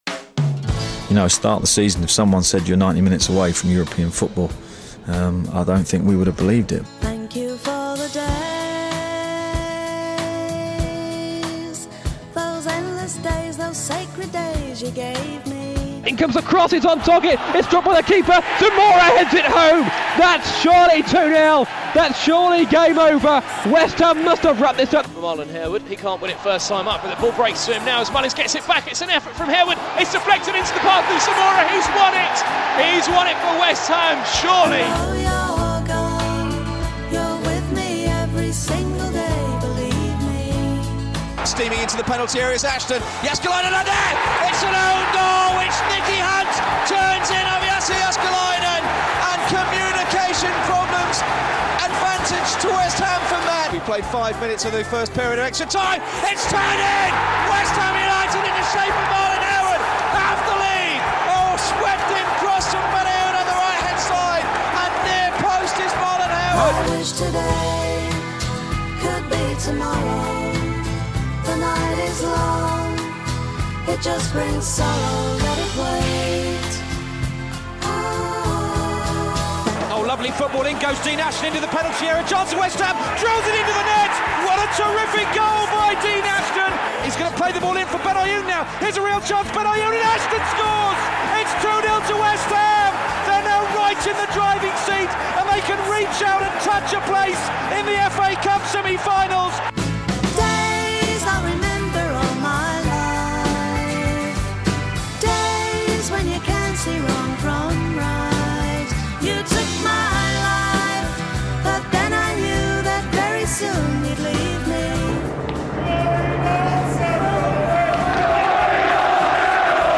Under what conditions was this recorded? West Ham fans can relive the run to the 2006 FA Cup Final under Alan Pardew with this selection from the BBC London Sport Archive.